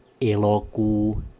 Ääntäminen
France: IPA: /ut/